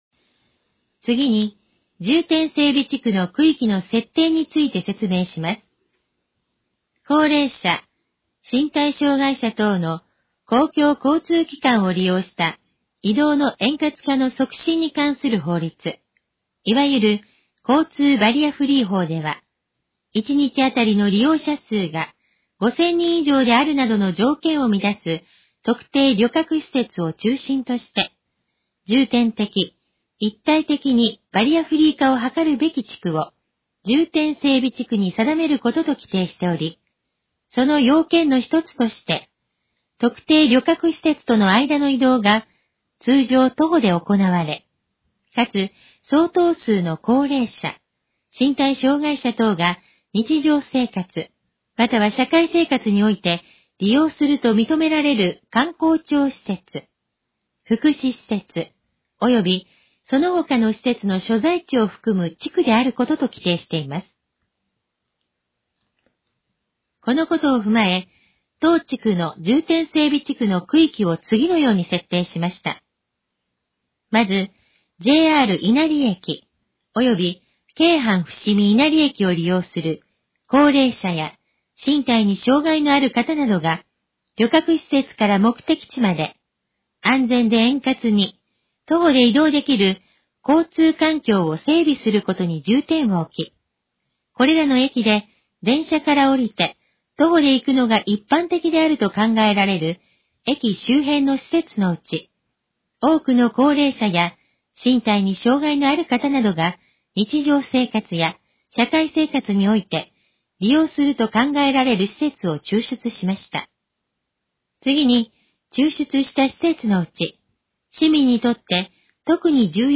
このページの要約を音声で読み上げます。
ナレーション再生 約338KB